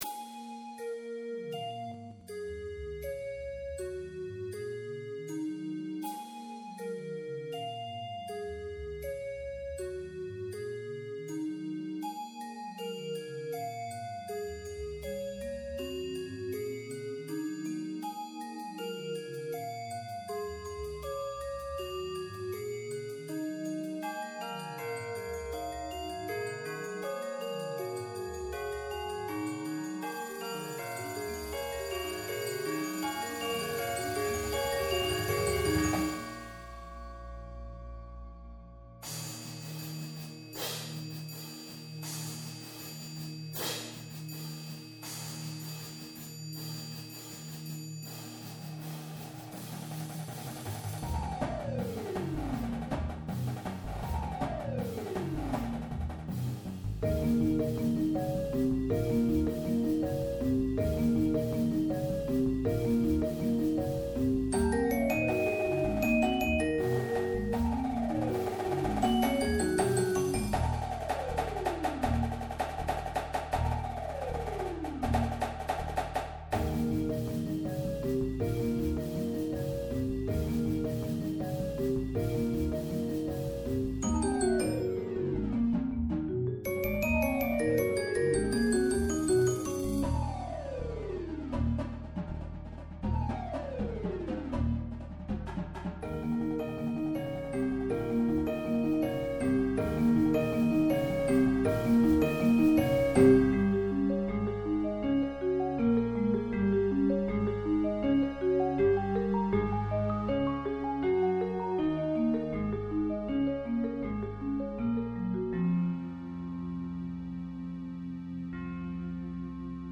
Beginning the piece with a single note
Bells
Marimba (3)
Vibes (2)
Chimes
Synth (2)
Electric Guitar
Electric Bass
Drum Set
Auxiliary Percussion
Snare
Tenors (quints)
Bass Drums (5)
Cymbals